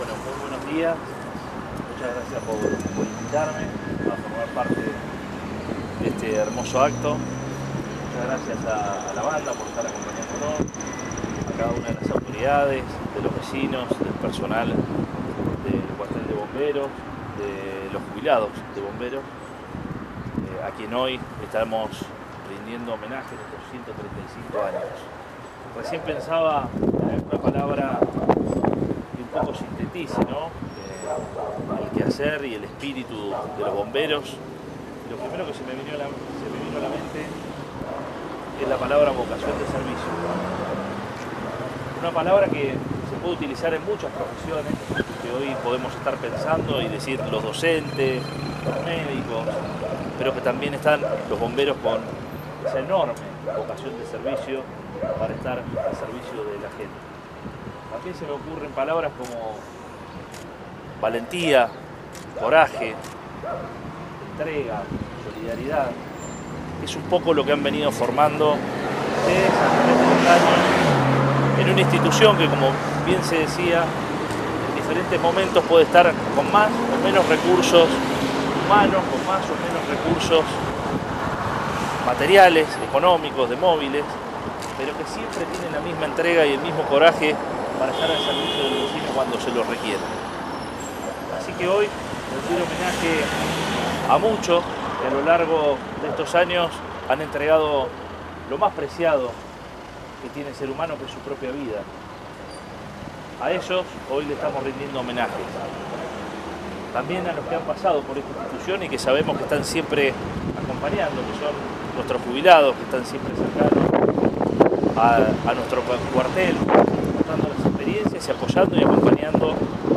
El encuentro protocolar, vale decir, tuvo lugar en el monumento que los homenajea, situado en avenidas 59 y 42, y participaron del mismo, además de funcionarios del equipo de Gobierno y el presidente del HCD, Guillermo Sánchez, representantes de los cuarteles de Necochea, Quequén y Juan N. Fernández, como así también exbomberos jubilados.
06-09-AUDIO-Arturo-Rojas-acto-bomberos.mp3